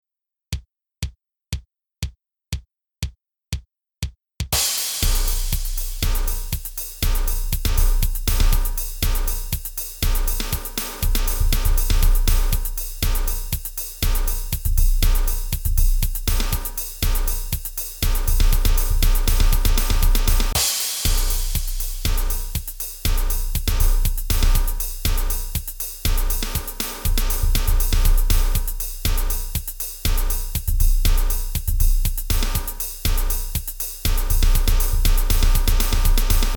ドラムキットの着信音、まるでリズムの魔法がスマートフォンに降り注ぐような感覚をお楽しみください。
この特別なドラムキットの着信音は、洗練されたビートと力強いリズムで日常の着信を一味違ったものに変えてくれます。